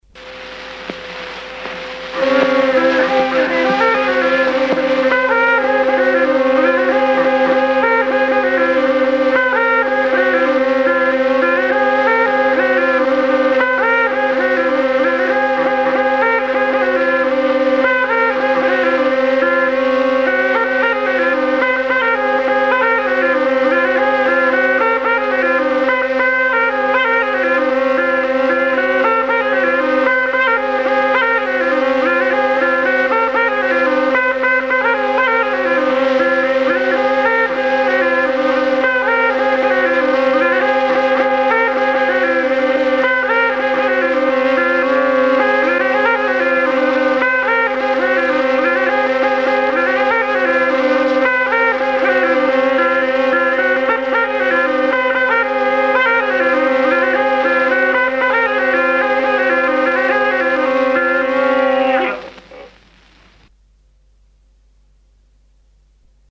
Orja polka